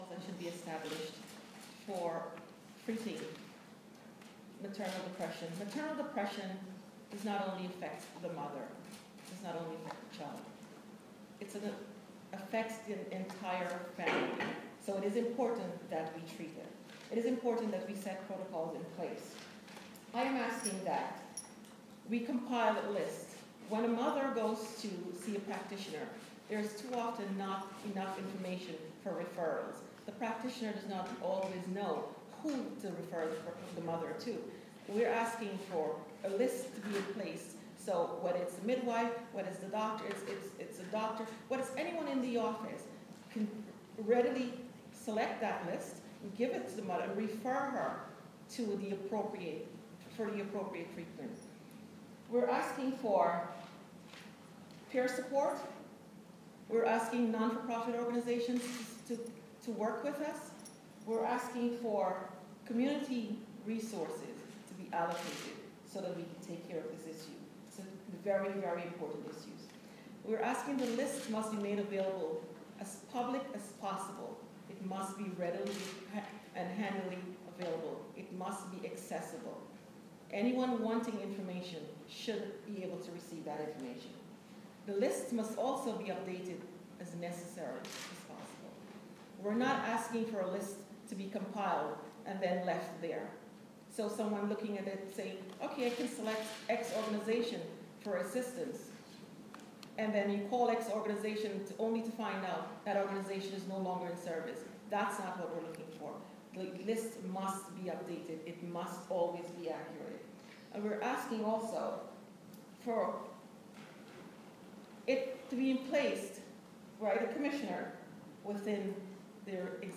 Senator Persaud's Audio From Press Conference